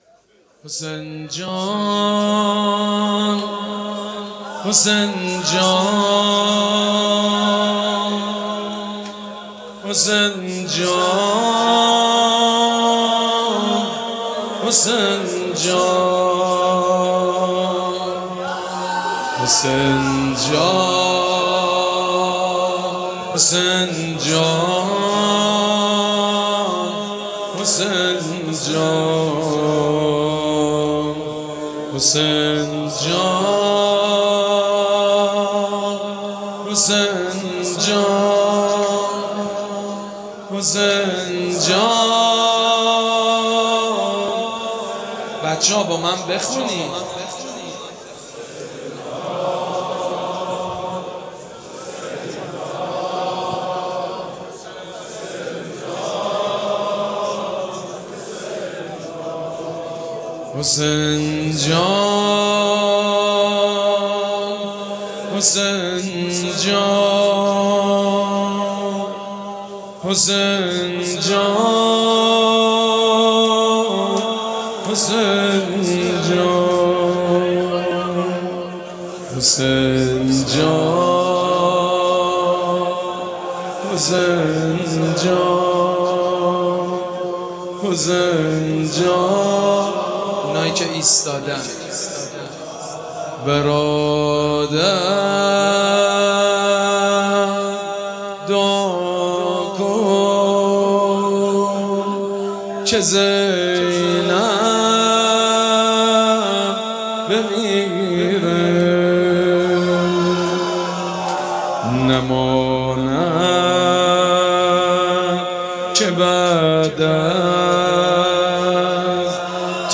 مراسم عزاداری شب دهم محرم الحرام ۱۴۴۷
مداح
حاج سید مجید بنی فاطمه